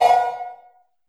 LR CB808  -R.wav